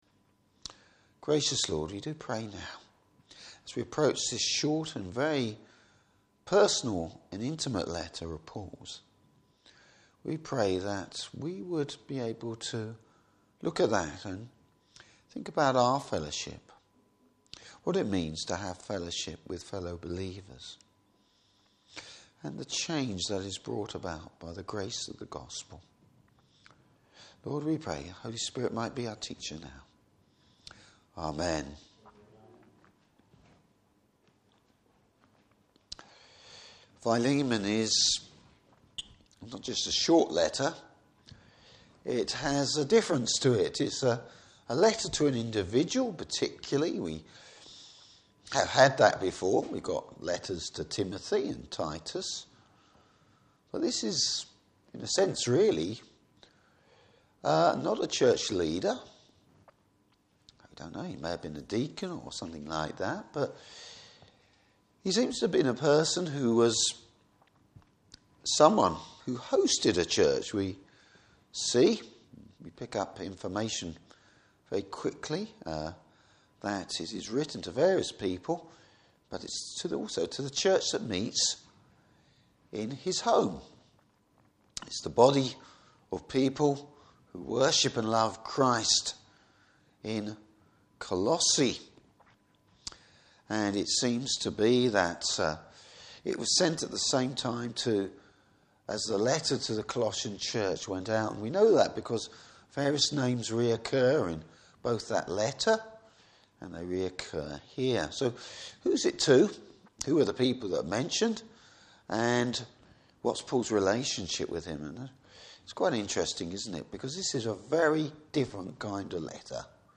Service Type: Evening Service The background to the letter. Topics: True biblical fellowship.